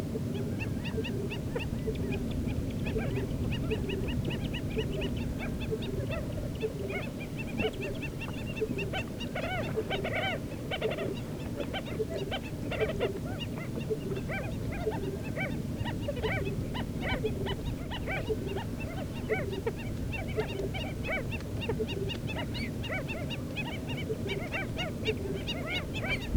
Oxyura vittata.wav